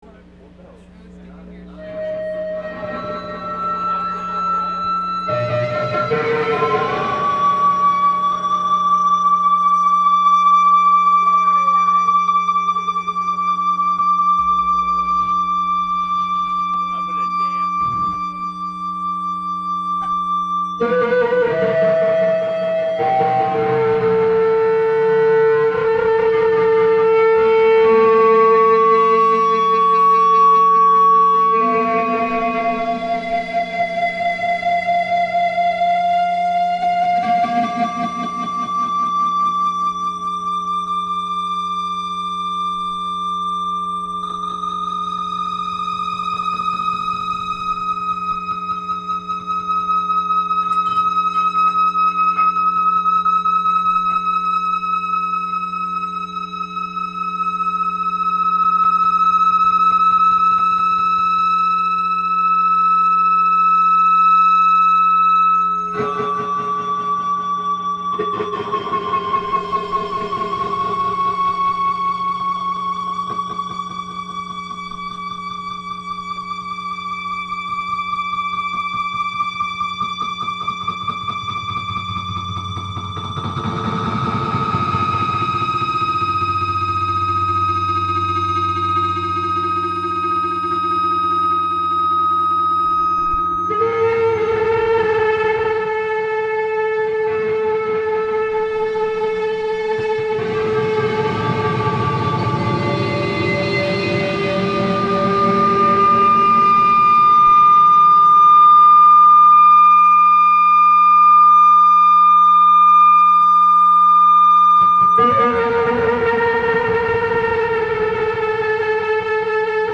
World Lines performance at Hangrr 18 (Audio)